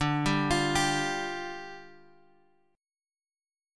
Dmbb5 chord {x 5 5 x 6 3} chord